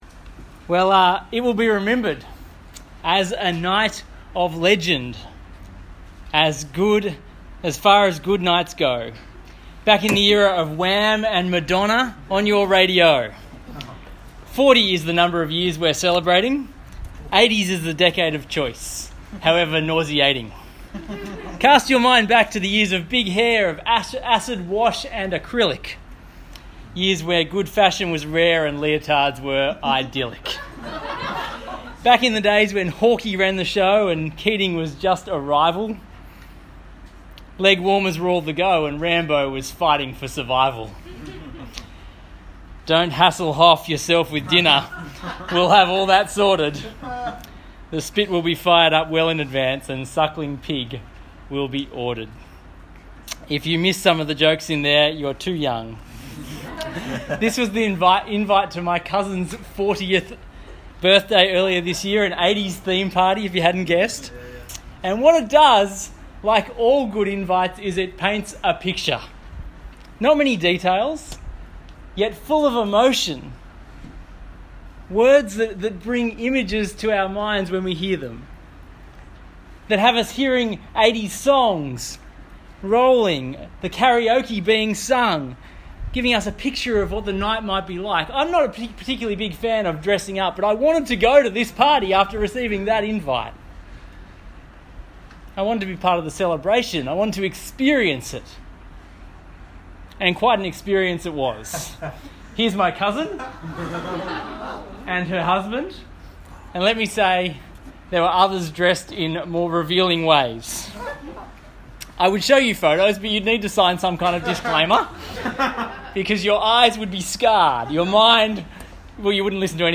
Talk Type: Getaway